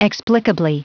Prononciation du mot explicably en anglais (fichier audio)
Prononciation du mot : explicably